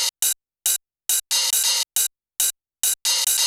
OP HH PLUS-R.wav